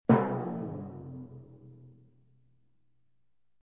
descargar sonido mp3 timpani